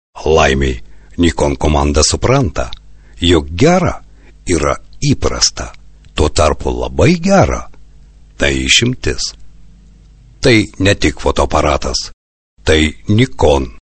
Sprecher litauisch für TV / Rundfunk / Industrie.
Sprechprobe: Industrie (Muttersprache):
Professionell voice over artist from Lithuania.